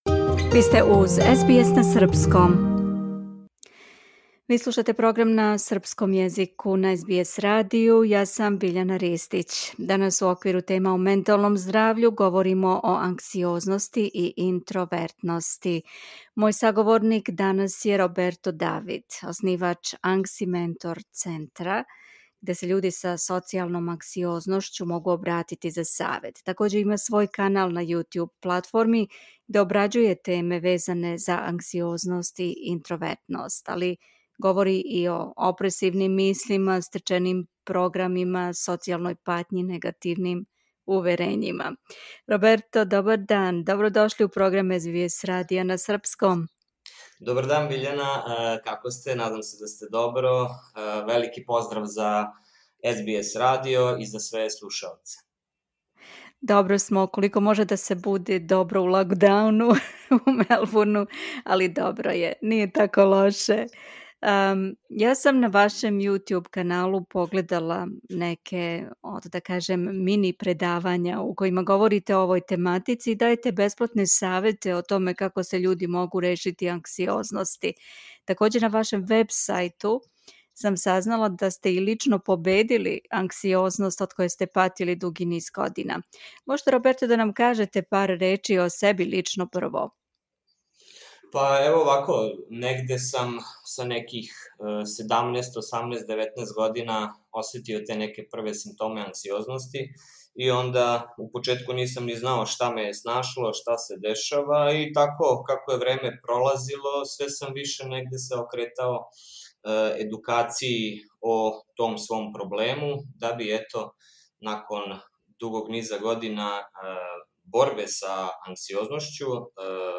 Слушајте цео разговор, кликните на слику.